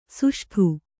♪ suṣṭhu